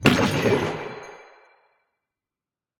Minecraft Version Minecraft Version 25w18a Latest Release | Latest Snapshot 25w18a / assets / minecraft / sounds / mob / glow_squid / death2.ogg Compare With Compare With Latest Release | Latest Snapshot
death2.ogg